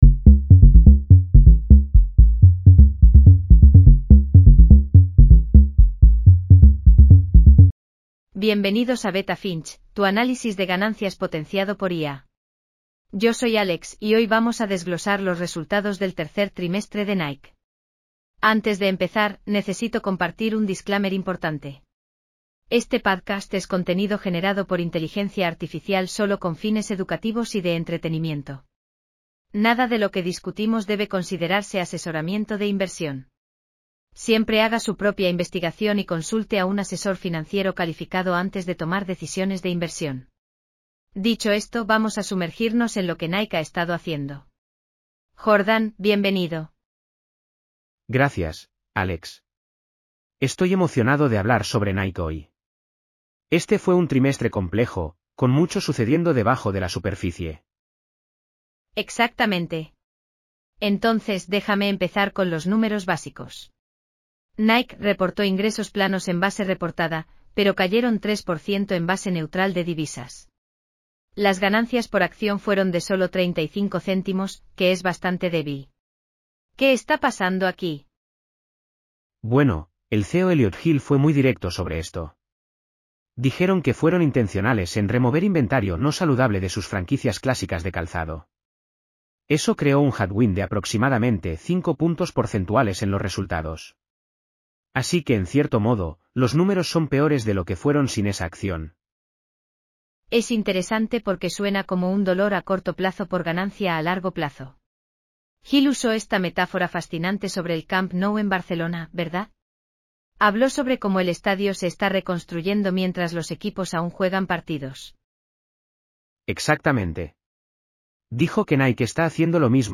Bienvenidos a Beta Finch, tu análisis de ganancias potenciado por IA.